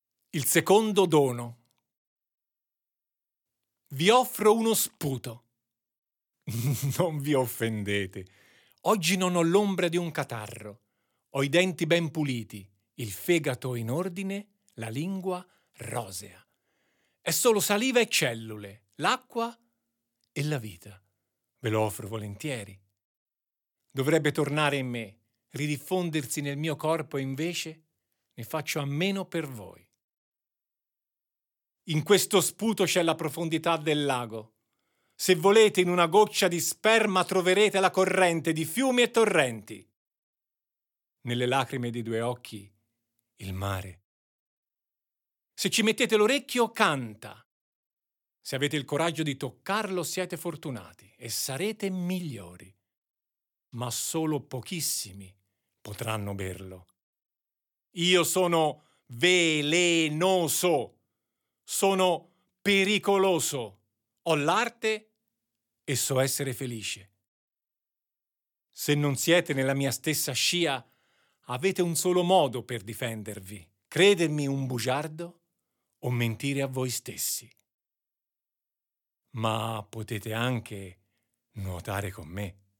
" ...SULLE NOSTRE OSSA" - EBOOK/AUDIOLIBRO